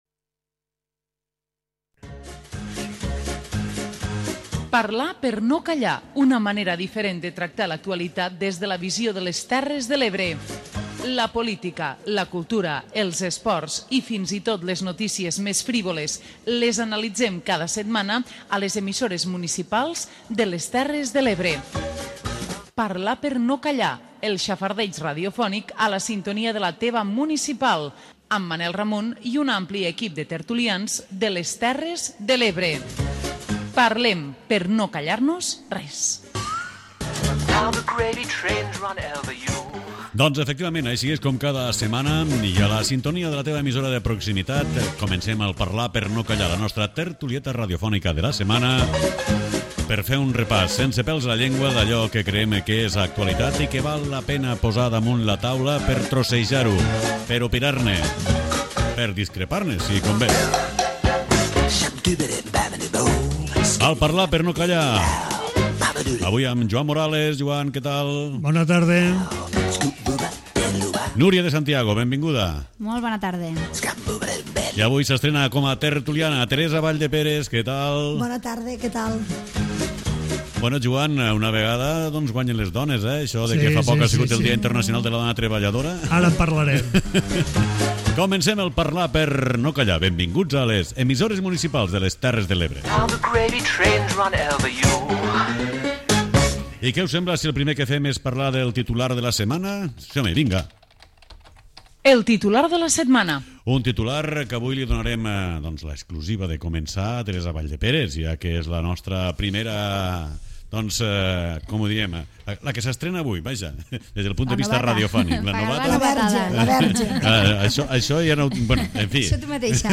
Parlar per no Callar, la tertúlia d'àmbit territorial d'EMUTE. 55 minuts de xerrada distesa amb gent que vol opinar. Busquem la foto, el titular i les carabasses de la setmana.